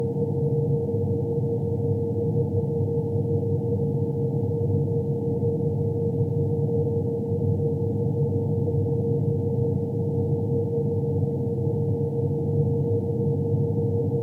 Pfeifendes Geräusch aus PC (Nicht die Lüfter)
Das Geräusch selber ist nicht das typische Knistern oder Summen, wie es typisch für Coil-Whine der Fall ist, sondern ein permanentes Pfeifgeräusch, das unabhängig vom Lastzustand der CPU oder GPU auftritt.
Zur besseren Wahrnehmung habe ich die Lautstärke erhöht. Das Pfeifen liegt etwa in einem Frequenzbereich von ca. 460 Hz und ist deutlich zu hören.
Am stärksten ist das Geräusch in der Nähe der Grafikkarte wahrzunehmen, aber ich kann auch nicht ausschließen, dass es von einer anderen Quelle stammt.